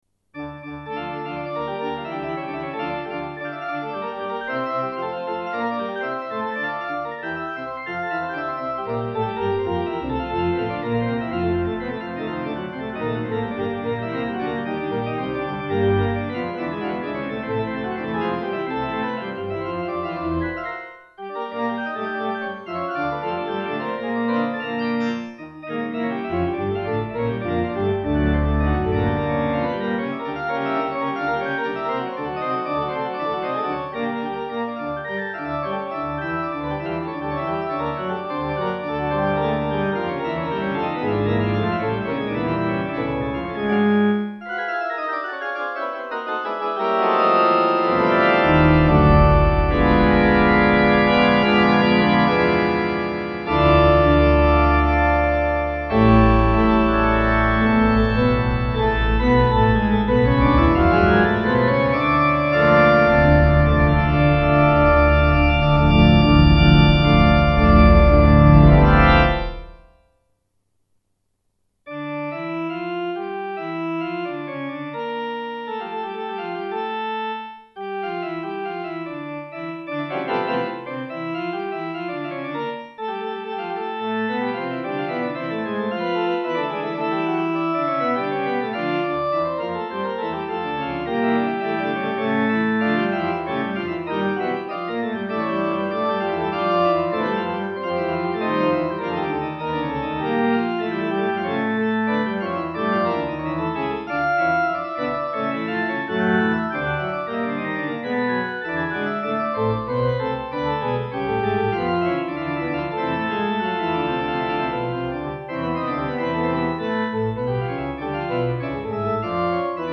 Tha Man plays da D major one - but I play the sheeyat in D minor which is much more furious key. 8)
I also had to use the organ sound on mah digtal piano cuz the piano is a bit sheeyat.
You sacrifced too much speed on the cadenza; the affekt was well noted tho. 8)